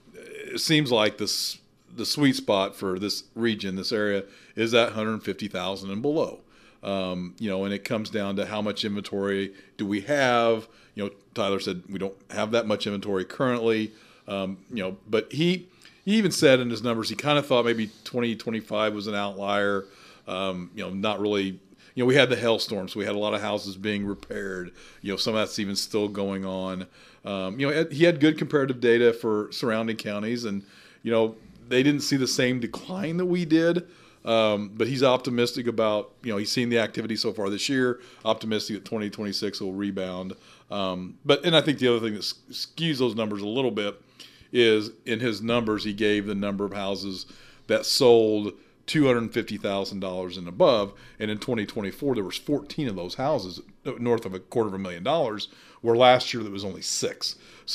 Speaking on our podcast “Talking about Vandalia,” Mayor Doug Knebel says the housing market is certainly a challenge for the community, especially to have “sweet spot” buying price for the community.